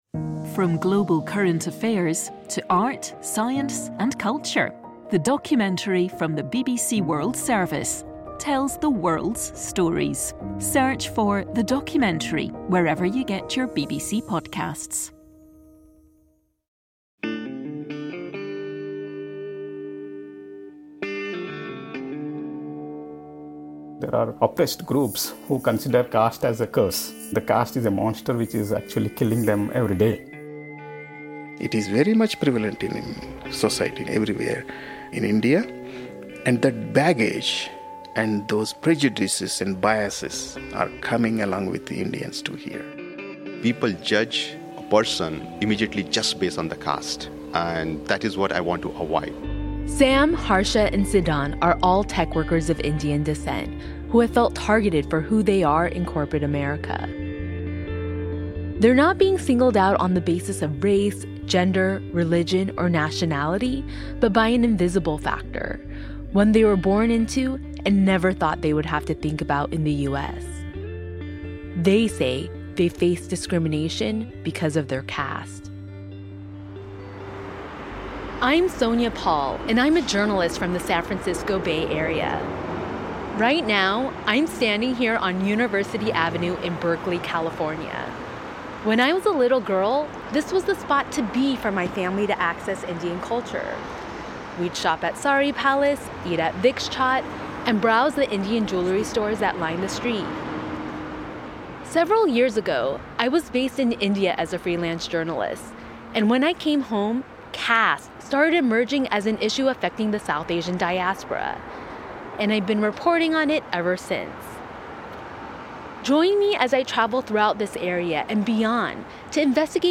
Presenter